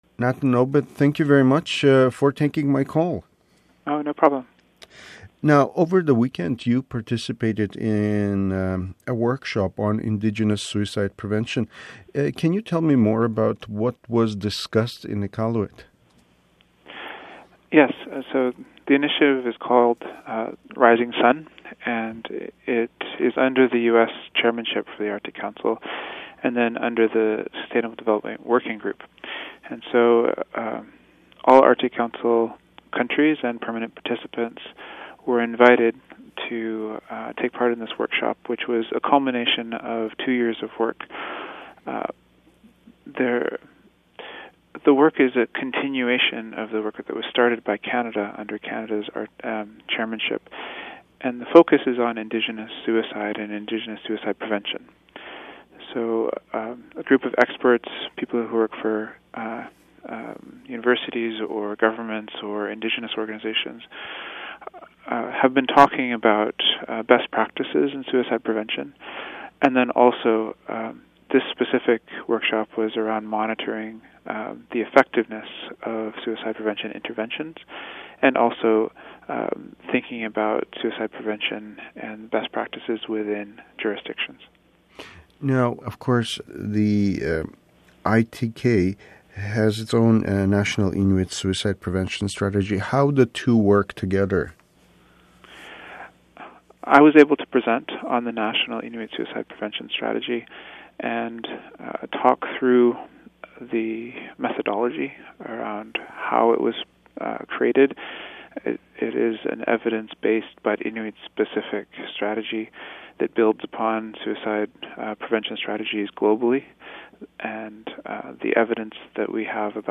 Click to listen to the full interview with ITK president Natan Obed